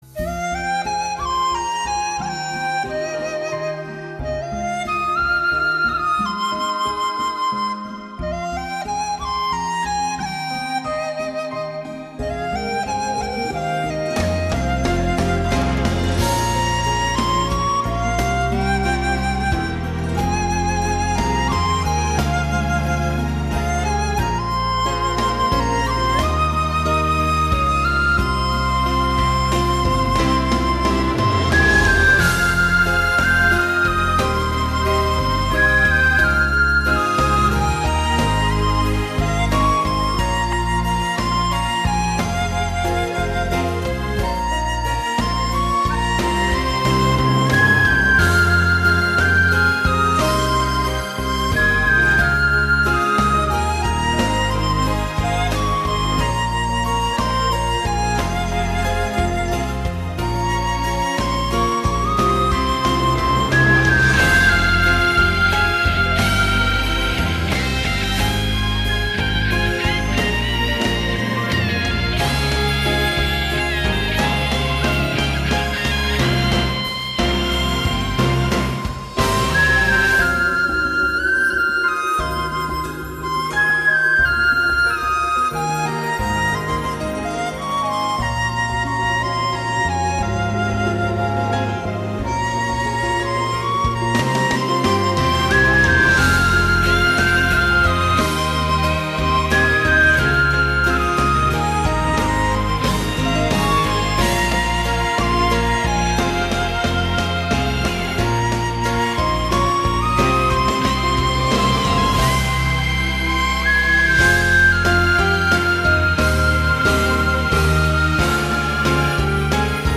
Sáo Trúc Dizi cực phẩm cổ phong, giai điệu buồn da diết.
bản không lời chất lượng cao